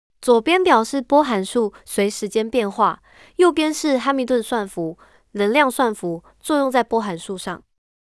pipeline_tts_fd9c5d9e.wav